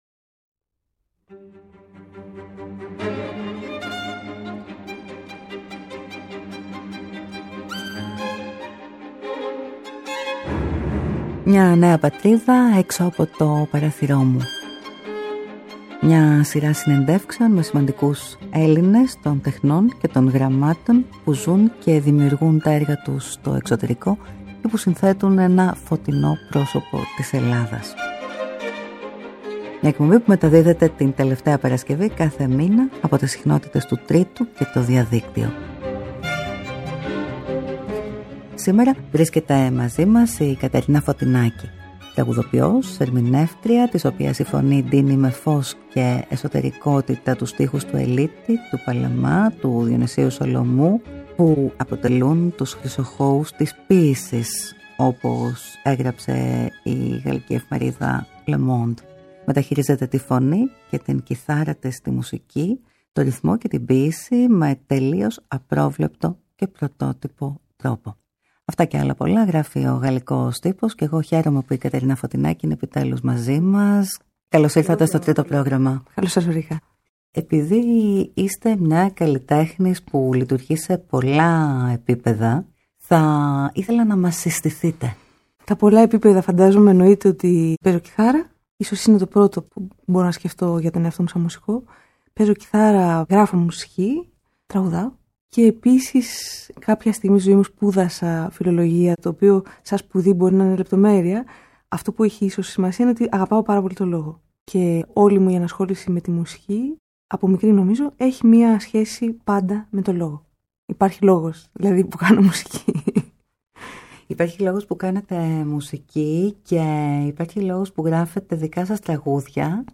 Μια σειρά συνεντεύξεων με σημαντικούς Έλληνες που ζουν και δημιουργούν τα έργα τους στο εξωτερικό και που συνθέτουν ένα φωτεινό και αισιόδοξο πρόσωπο της Ελλάδας.